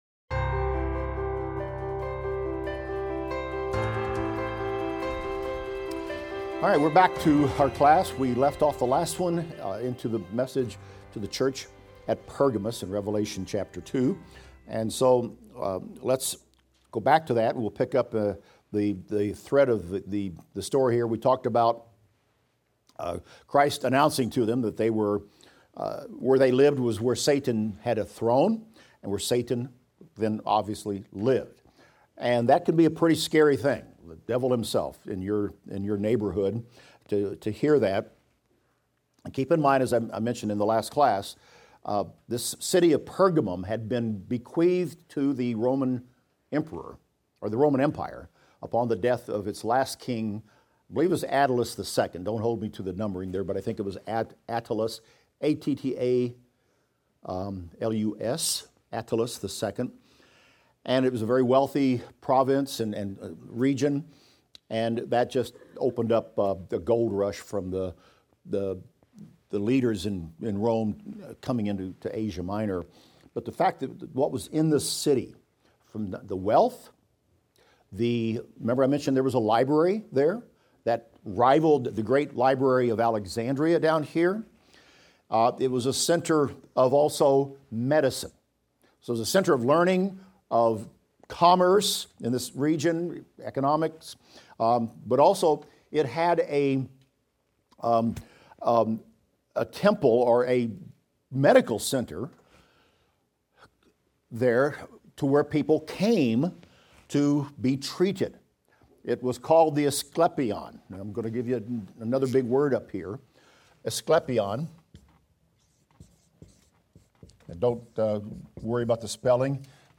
Revelation - Lecture 30 - Audio.mp3